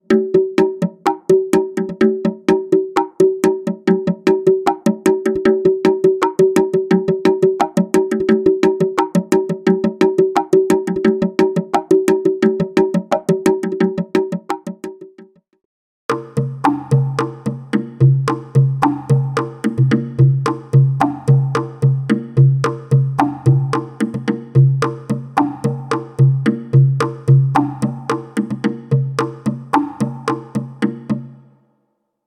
Drum Modelling Examples
bongos tablas + tamburi riverbero
bongos_tablas + tamburi riverbero.mp3